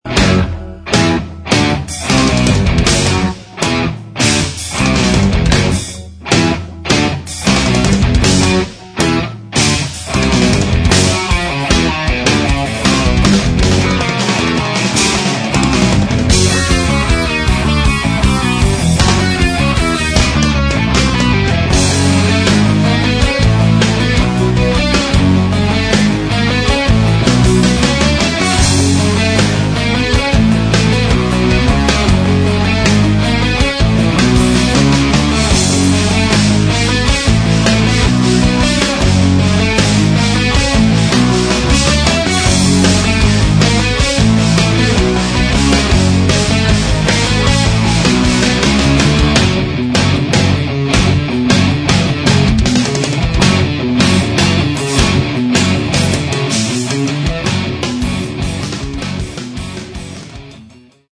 Metal
клавиши, фортепьяно